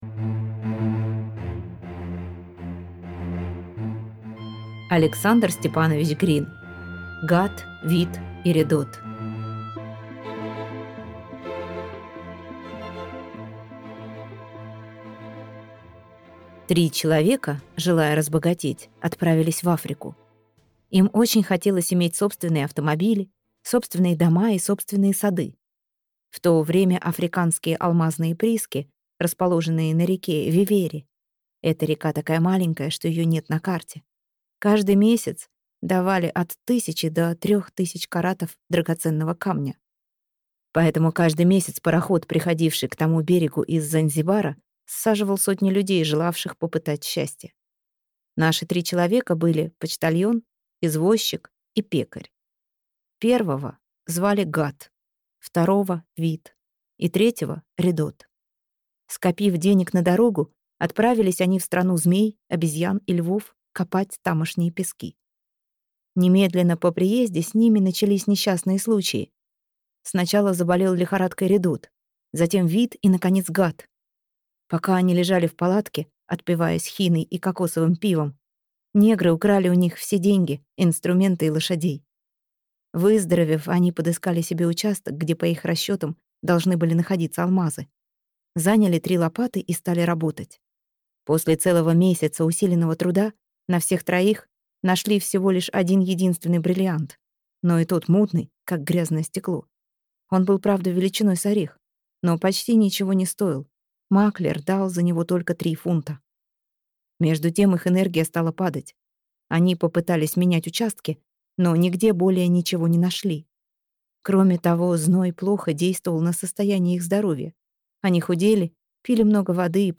Аудиокнига Гатт, Витт и Редотт | Библиотека аудиокниг